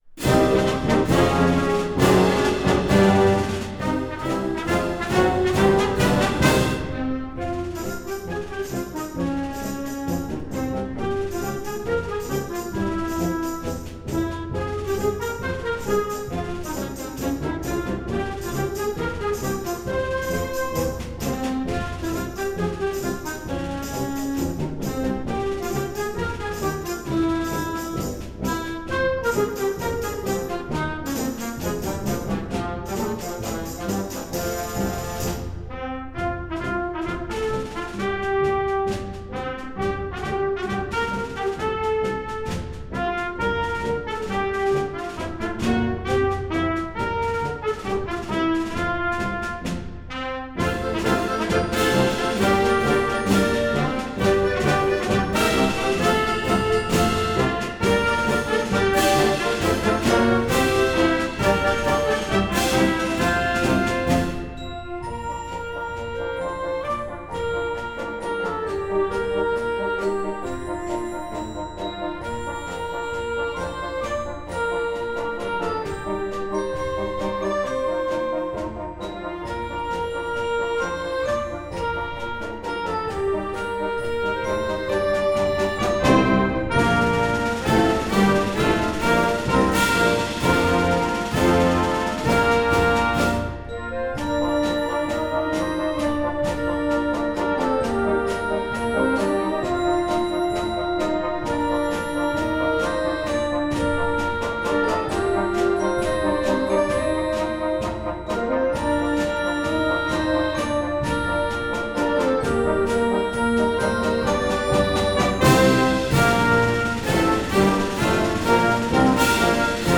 Gattung: Marsch für Jugendblasorchester
Besetzung: Blasorchester